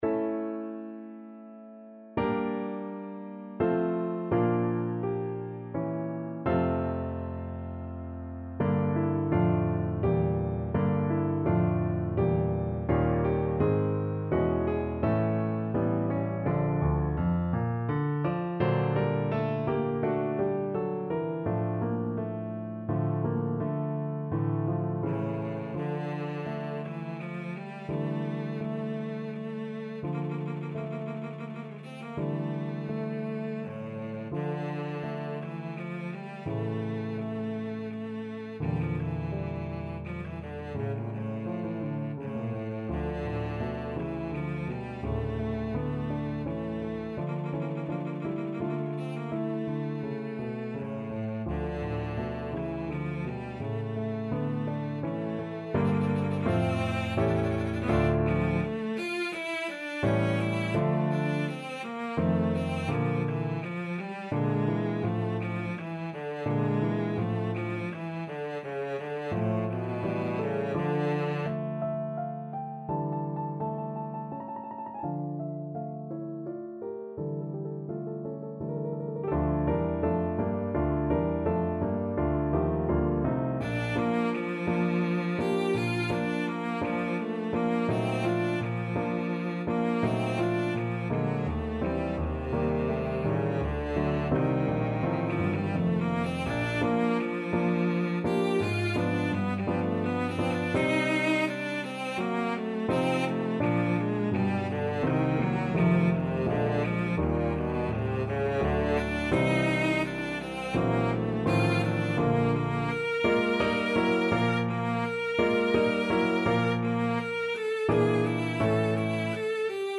3/4 (View more 3/4 Music)
Andante =84
Classical (View more Classical Cello Music)